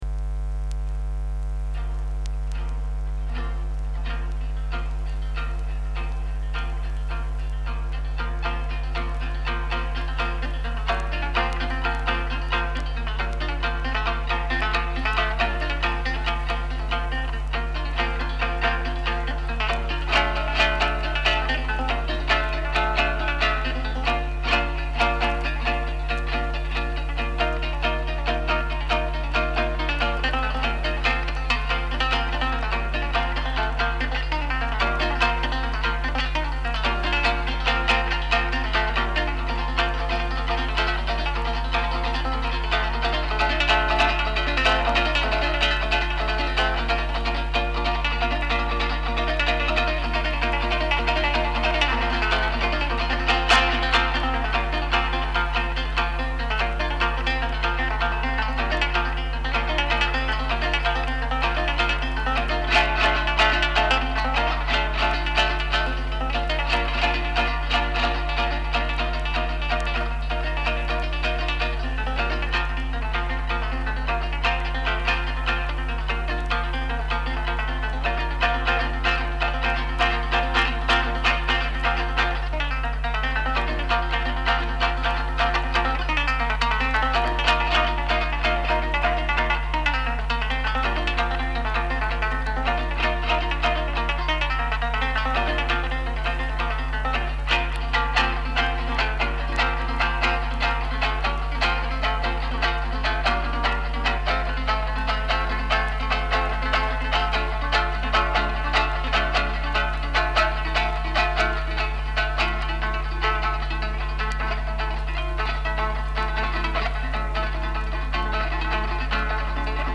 Georgian Folklore